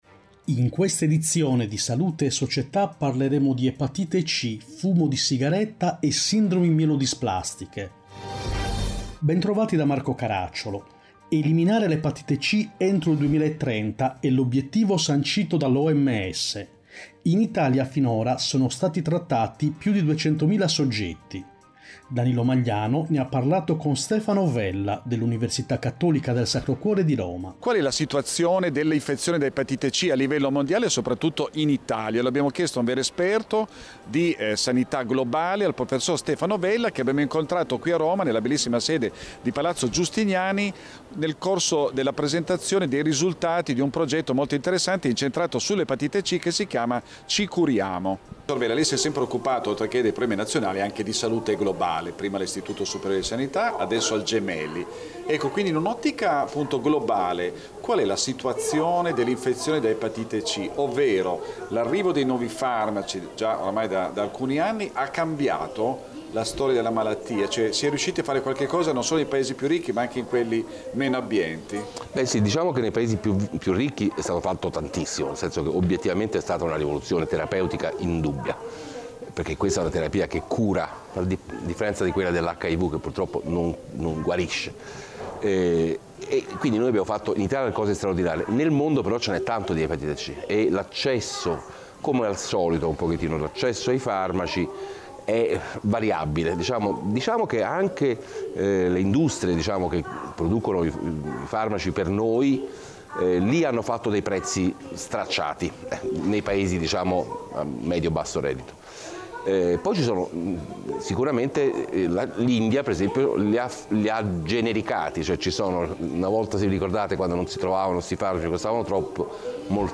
In questa edizione: 1. Epatite C, la situazione attuale in Italia 2. Fumo di sigaretta, perché è difficile smettere di fumare 3. Sindromi mielodisplastiche, le ultime novità Interviste